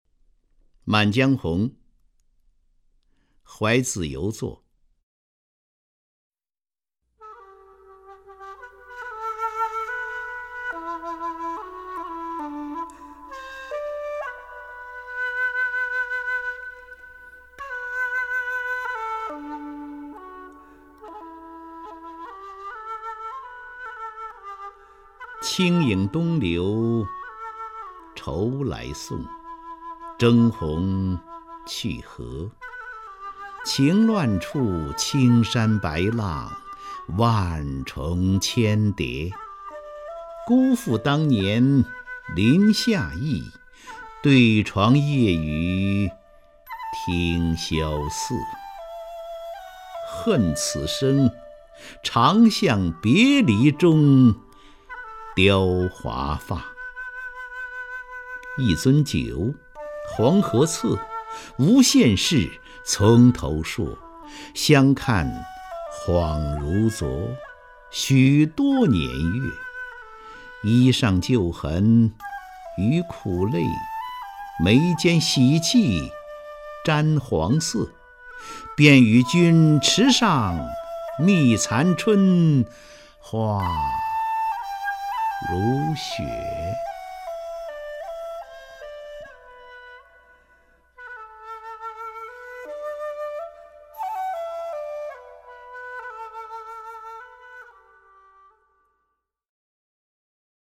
张家声朗诵：《满江红·怀子由作》(（北宋）苏轼)　/ （北宋）苏轼
名家朗诵欣赏 张家声 目录
ManJiangHongHuaiZiYouZuo_SuShi(ZhangJiaSheng).mp3